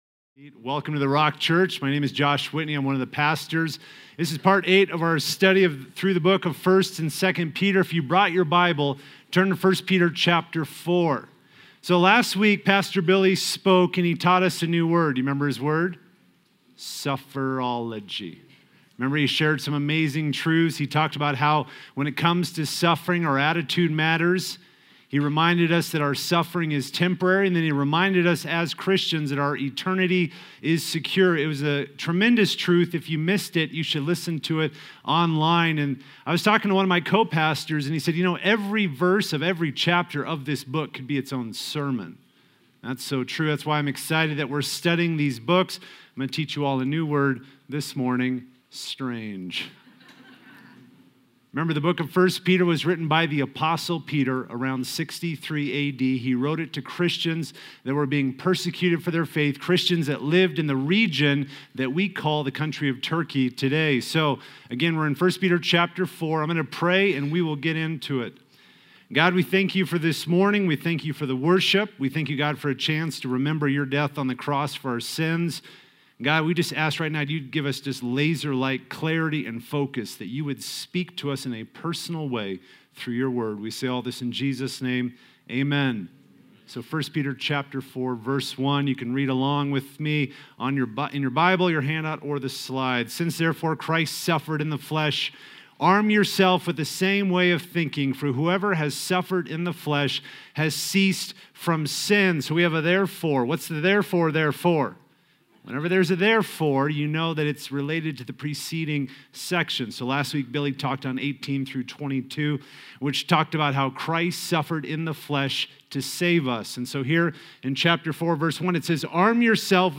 A message from the series "Your Love Remains."